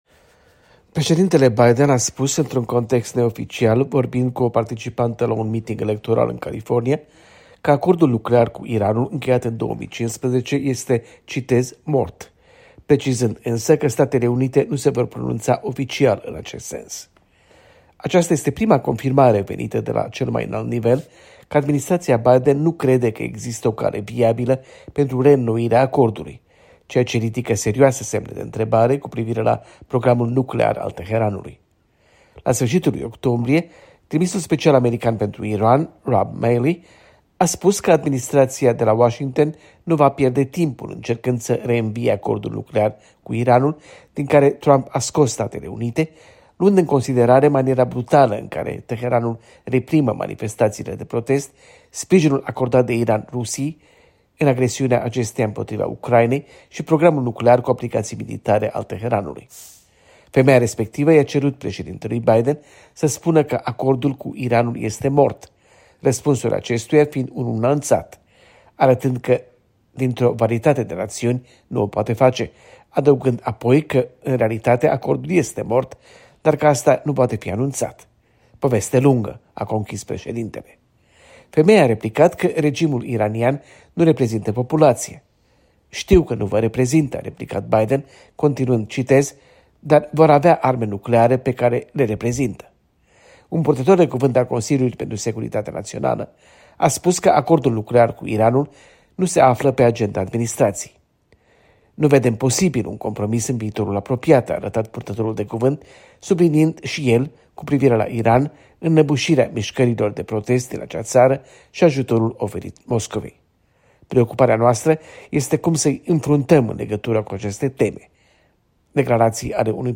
Președintele Biden a spus, într-un context neoficial, vorbind cu o participantă la un miting electoral în California, că acordul nuclear cu Iranul, încheiat în 2015, este „mort”, precizând însă că Statele Unite nu se vor pronunța oficial, în acest sens.